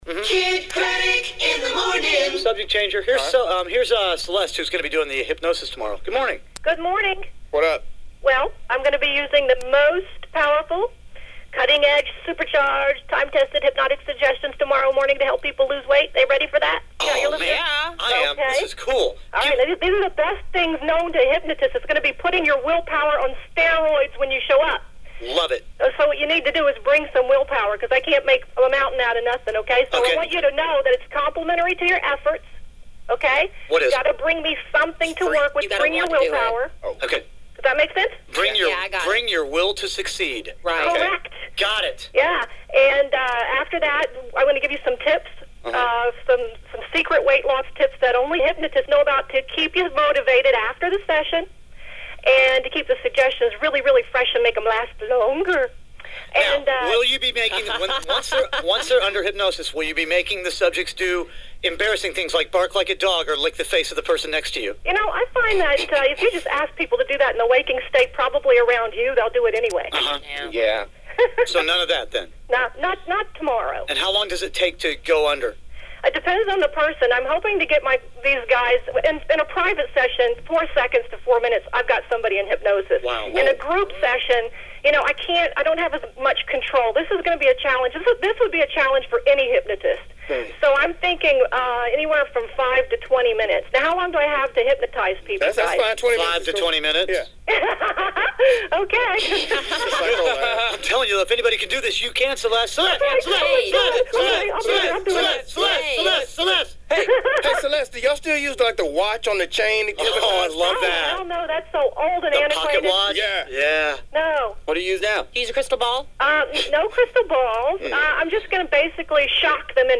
I’ve been featured on the nationally syndicated radio morning show “Kidd Kraddick in the Morning”. I hypnotized about 50 people for weight loss!